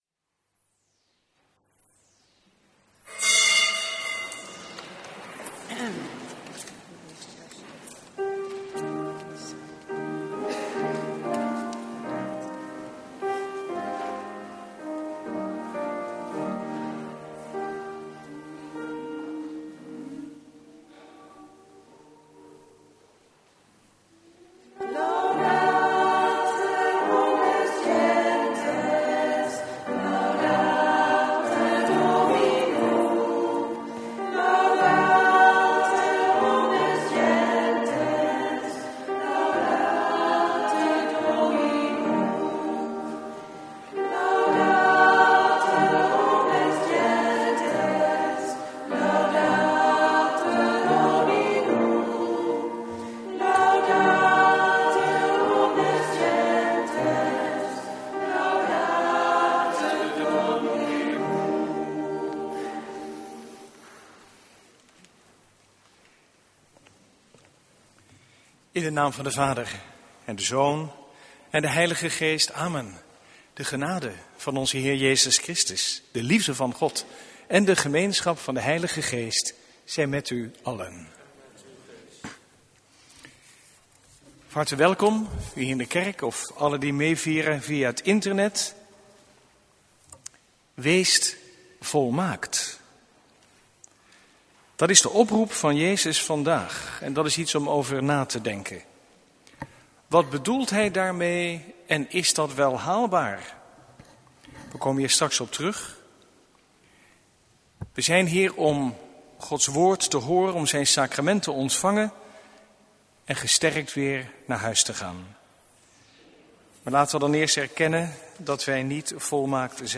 Eucharistieviering vanuit De Goede Herder te Wassenaar (MP3)